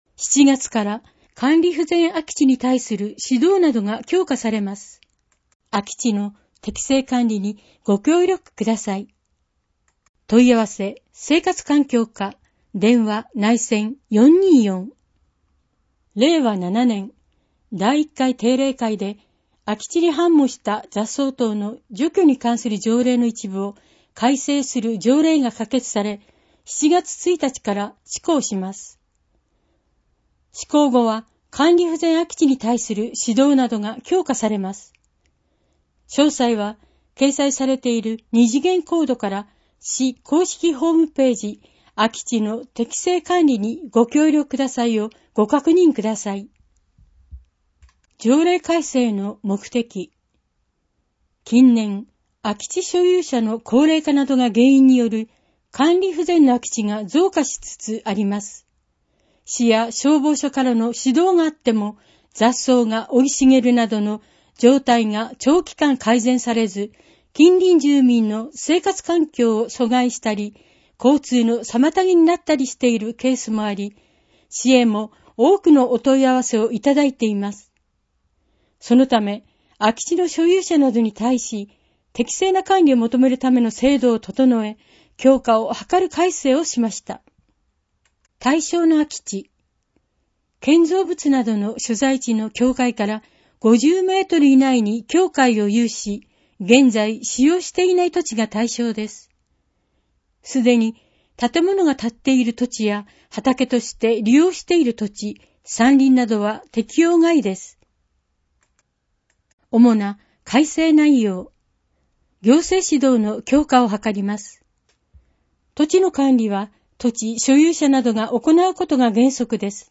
※MP3データは「龍ケ崎朗読の会」のご協力により作成しています。